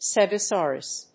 /ˌstɛgʌˈsɔrʌs(米国英語), ˌstegʌˈsɔ:rʌs(英国英語)/